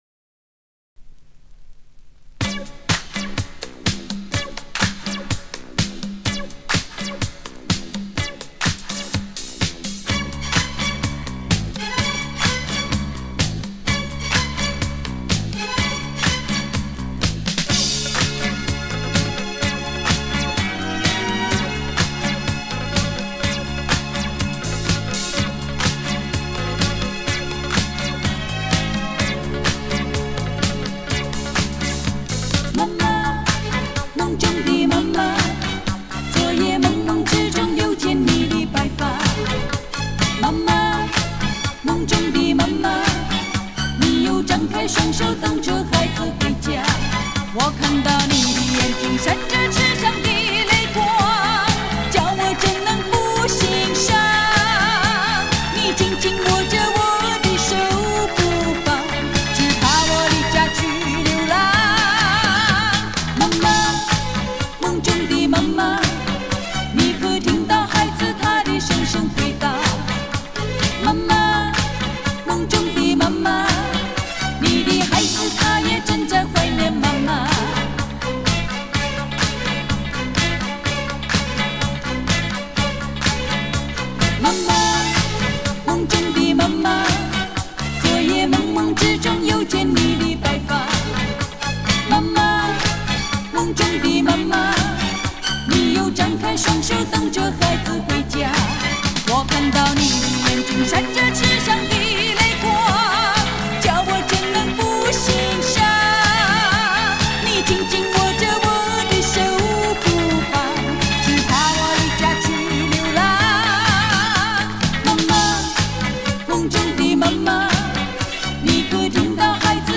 LP版
国语歌曲